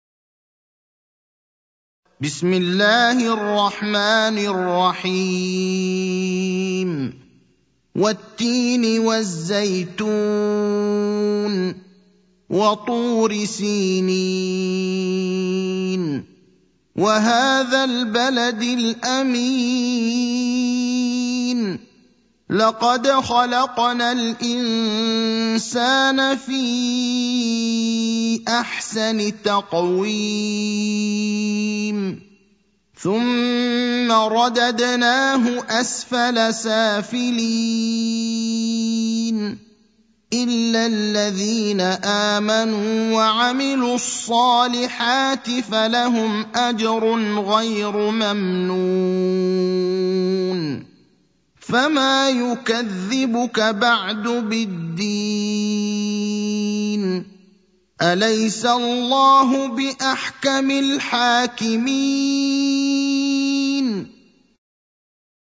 المكان: المسجد النبوي الشيخ: فضيلة الشيخ إبراهيم الأخضر فضيلة الشيخ إبراهيم الأخضر التين (95)